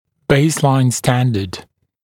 [‘beɪslaɪn ‘stændəd][‘бэйслайн ‘стэндэд]основное стандарт, базовый стандарт